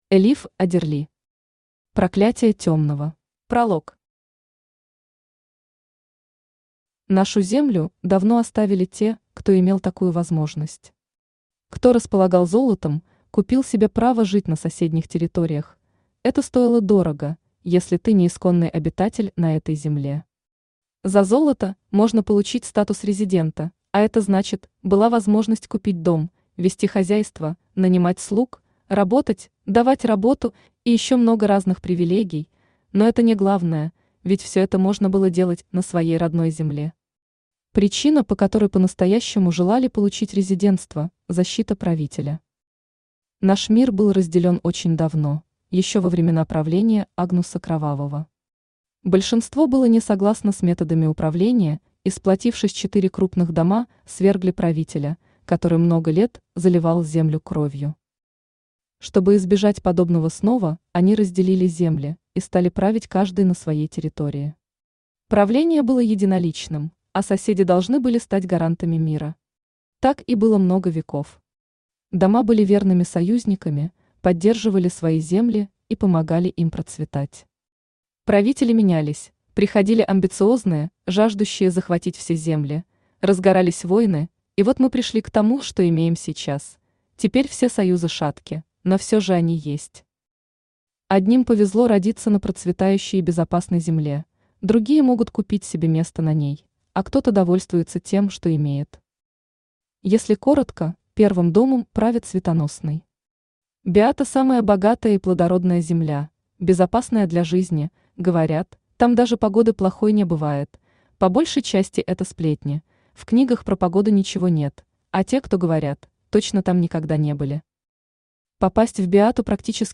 Aудиокнига Проклятие Темного Автор Элиф Аддерли Читает аудиокнигу Авточтец ЛитРес. Прослушать и бесплатно скачать фрагмент аудиокниги